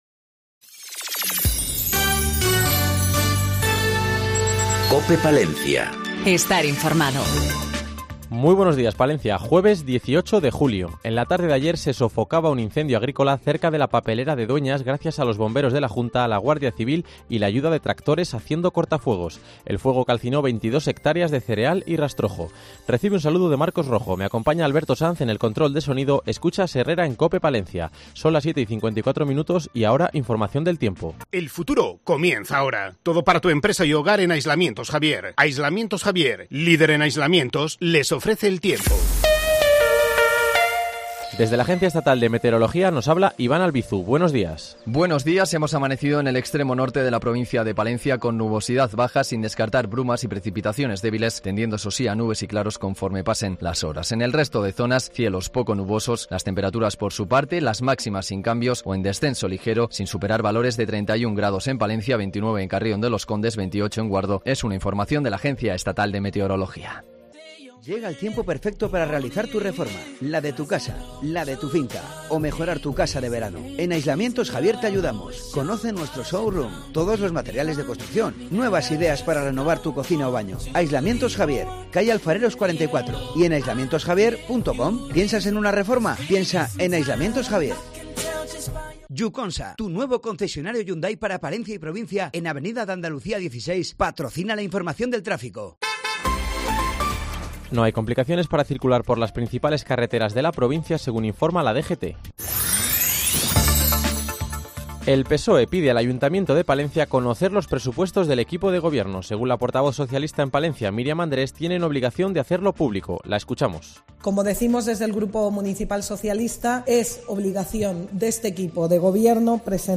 INFORMATIVO 07,50 HORAS 18-07-19